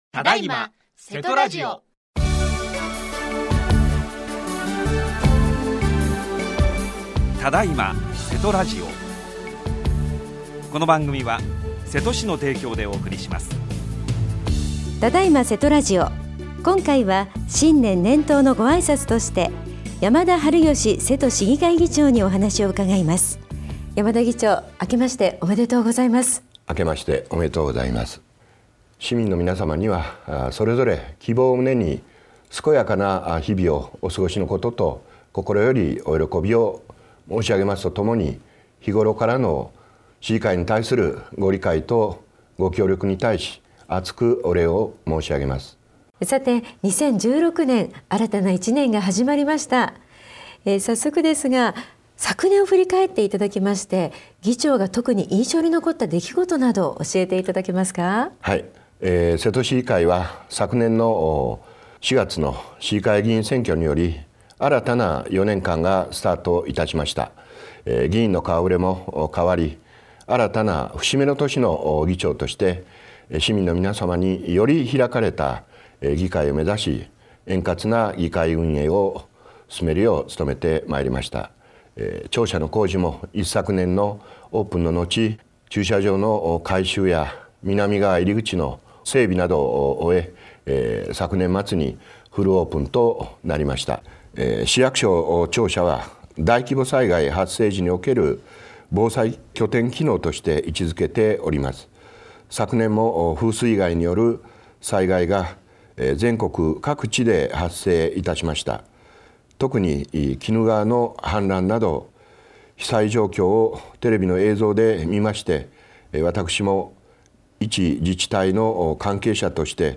只今！せとラジオ 今回は新年、年頭のご挨拶として、山田治義 瀬戸市議会議長にお話を伺いました。
特別番組 | radiosanq-hp | 2016年1月4日 9:36 AM